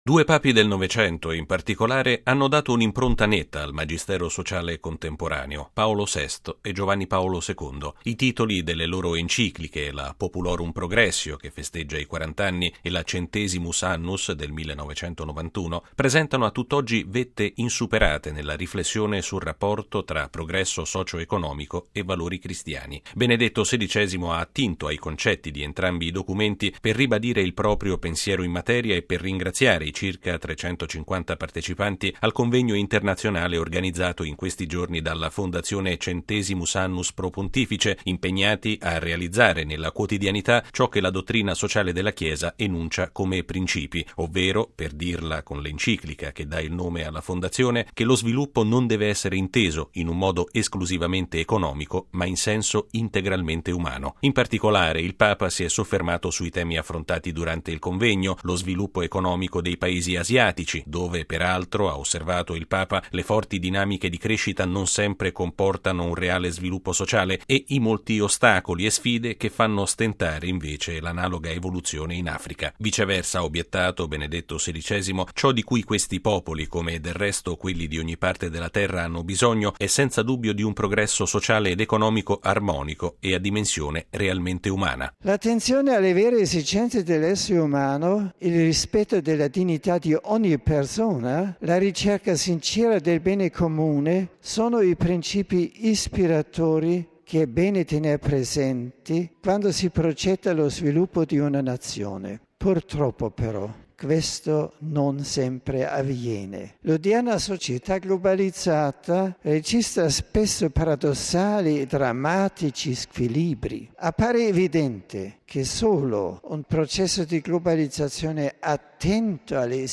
E’ la convinzione espressa questa mattina da Benedetto XVI ai partecipanti al Convegno della Fondazione Centesimus Annus-Pro Pontifice”, un organismo creato nel 1993 da Giovanni Paolo II allo scopo di promuovere la Dottrina sociale della Chiesa nei settori professionale e imprenditoriale, favorendo l’azione solidale della Chiesa e del Papa nel mondo.